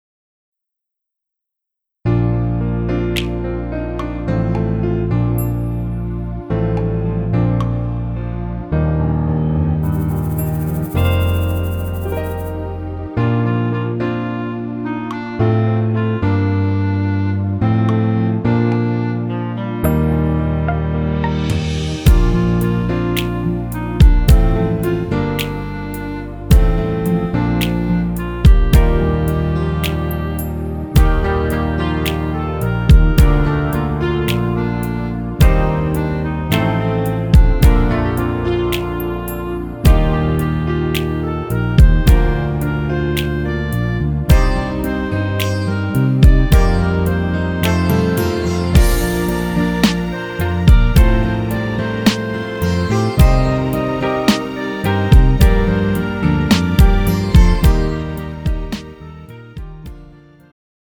음정 -2키
장르 축가 구분 Pro MR